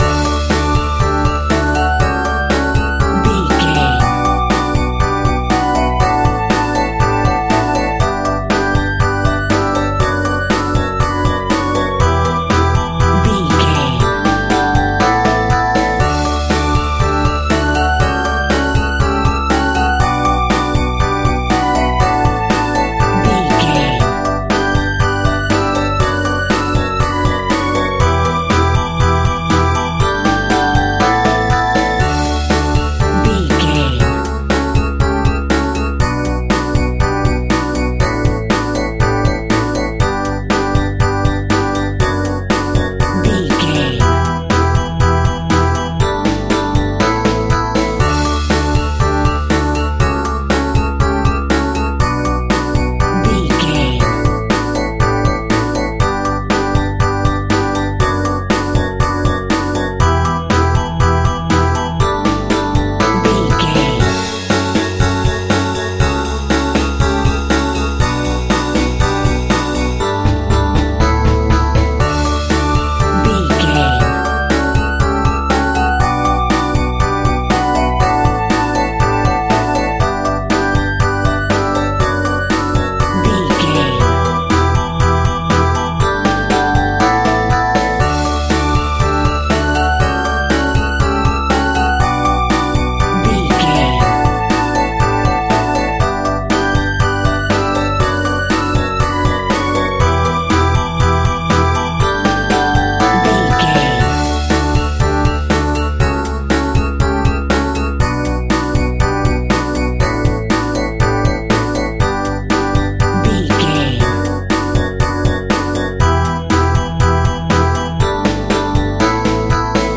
Aeolian/Minor
pop rock
indie pop
fun
energetic
uplifting
drums
bass guitar
piano
hammond organ
synth